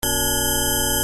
Wówczas drugi dźwięk pomimo tego, że jest tak jakby szybciej odtwarzany (2n zamiast n) posiada niższe brzmienie: